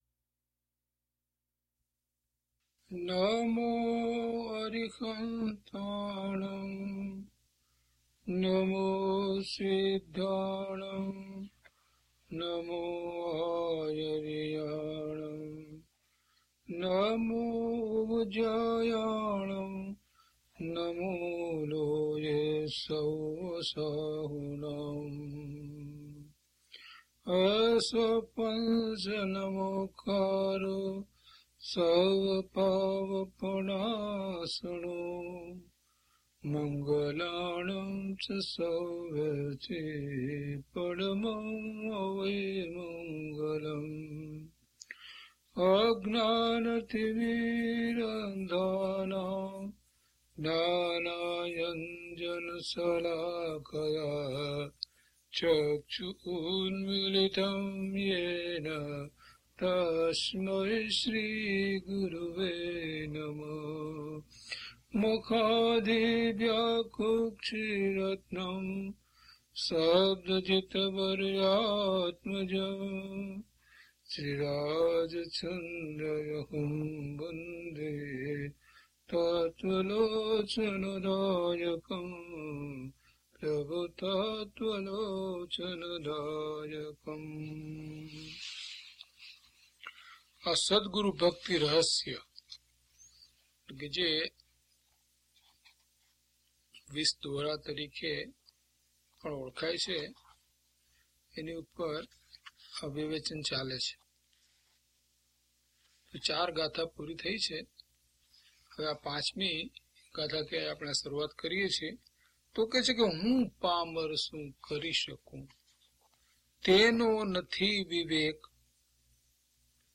Audio title: DHP004 He Prabhu He Prabhu Gatha 5 to 12 - Pravachan.mp3